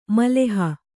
♪ maleha